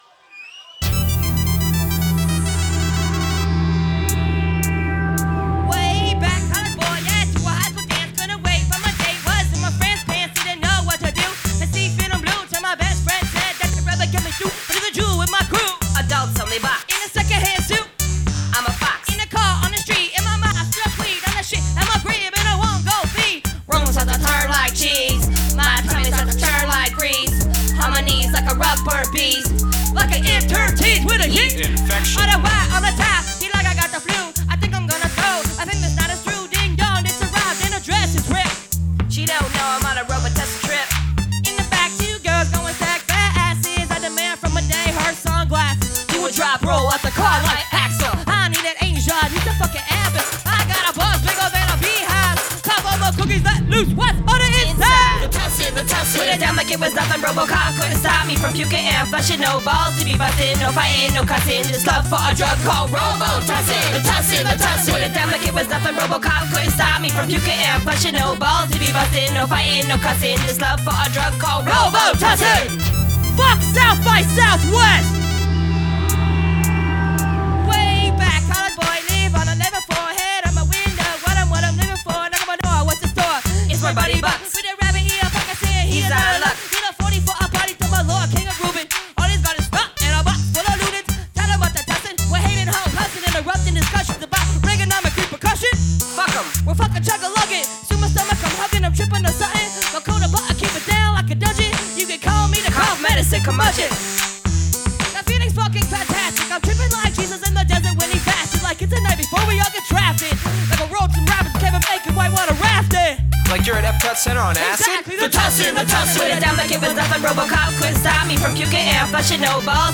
austin, tx at emo's on july 29th 2005